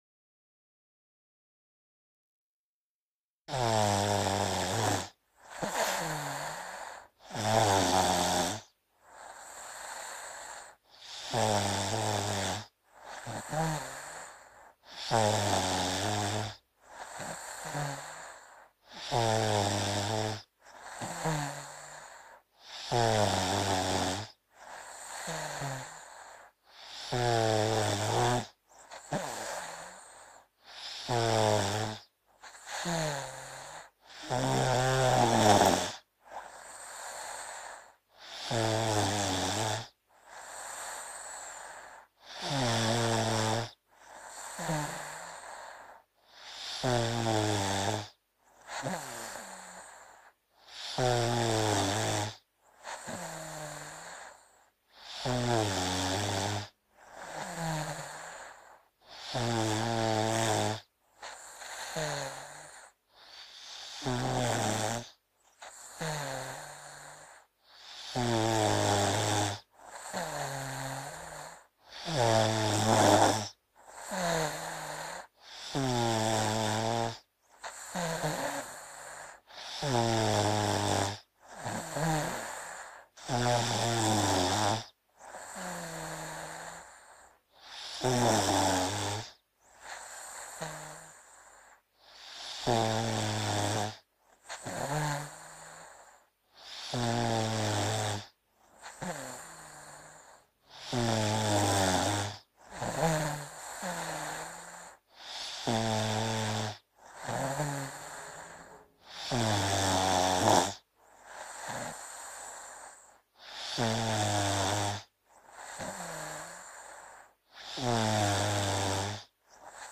دانلود آهنگ خروپف 4 از افکت صوتی انسان و موجودات زنده
دانلود صدای خروپف 4 از ساعد نیوز با لینک مستقیم و کیفیت بالا
جلوه های صوتی